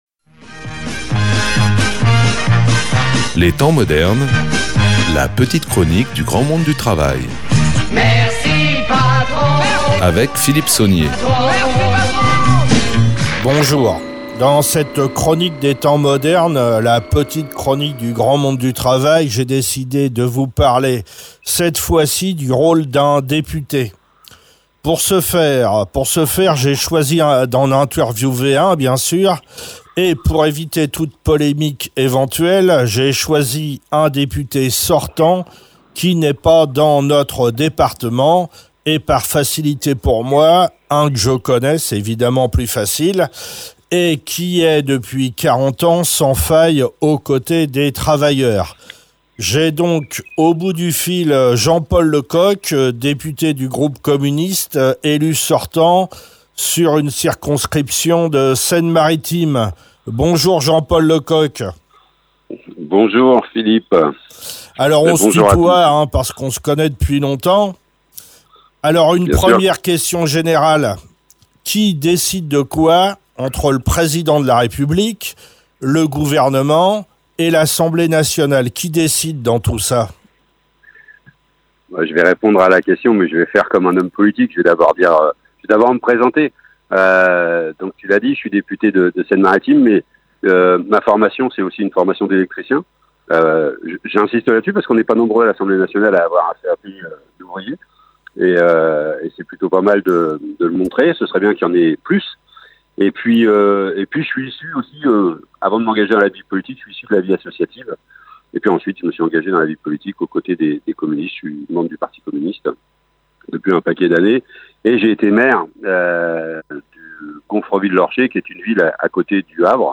Quel est le rôle d’un député et de l’assemblée nationale ? Interview de Jean Paul Lecoq.
Invité(s) : Jean Paul Lecoq, député du groupe communiste , élu sortant sur une circonscription de Seine Maritime